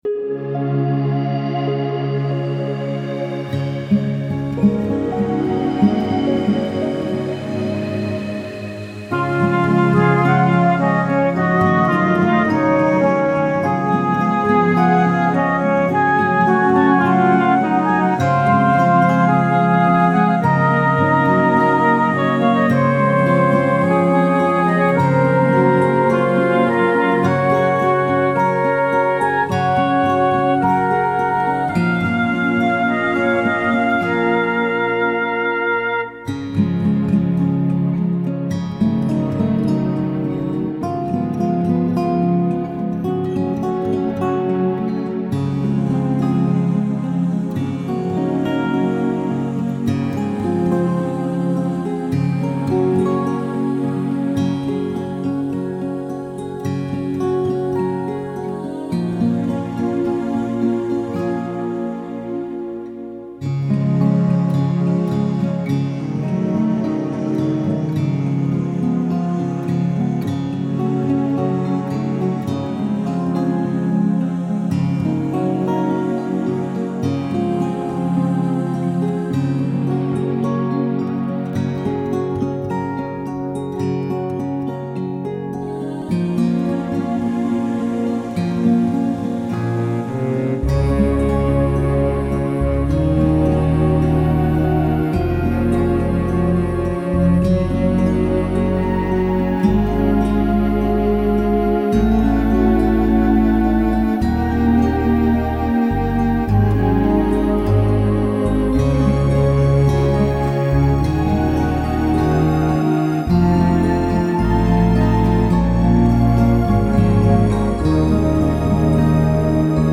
It has such a mystical quality, with descriptive words and eerie music.
Below is the arrangement without a vocal:
alabaster-seashell-3-arrangement-with-guitar.mp3